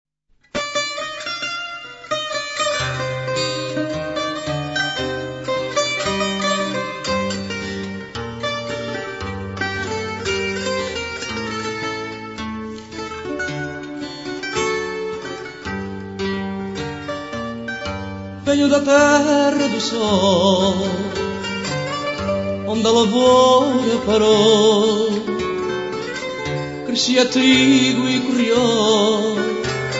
chitarra portoghese
• fado
• registrazione sonora di musica